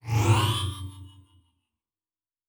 pgs/Assets/Audio/Sci-Fi Sounds/Doors and Portals/Teleport 9_1.wav at 7452e70b8c5ad2f7daae623e1a952eb18c9caab4
Teleport 9_1.wav